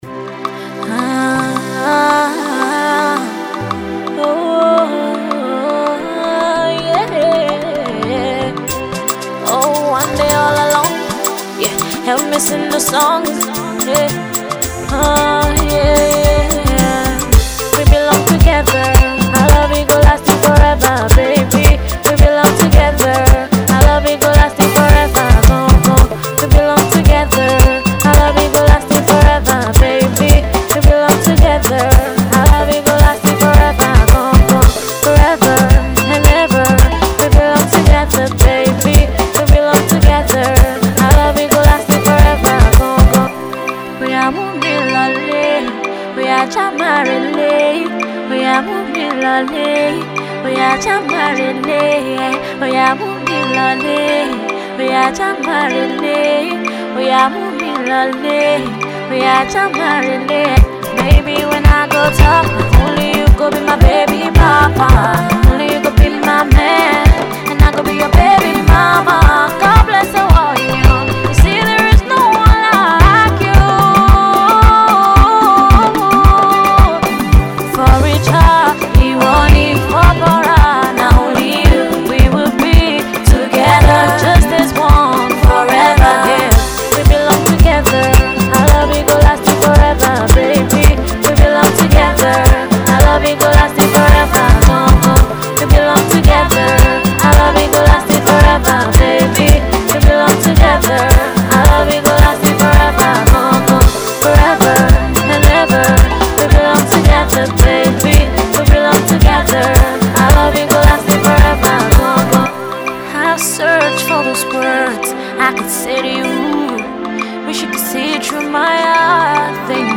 Alternative Pop
female singer